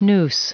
Prononciation du mot : noose
noose.wav